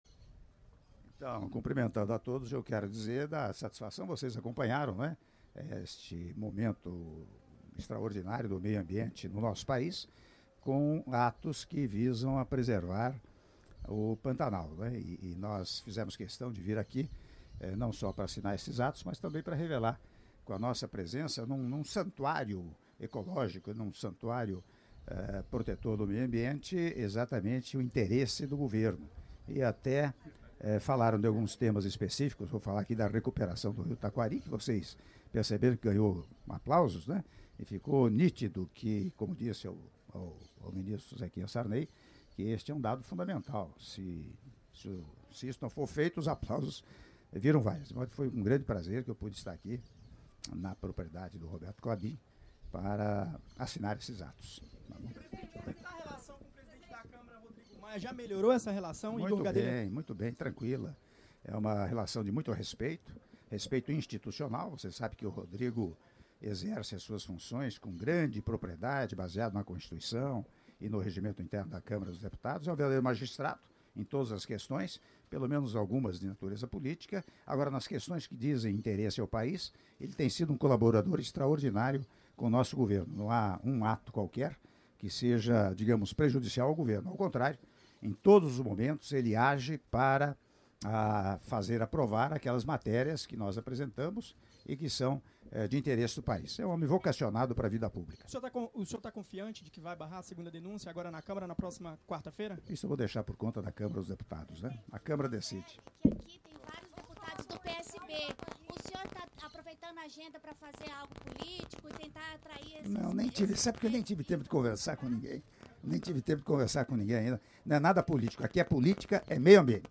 Áudio da entrevista coletiva concedida pelo Presidente da República, Michel Temer, após Cerimônia de Encerramento do II Encontro da Carta Caiman - (02min02s) - Miranda/MS — Biblioteca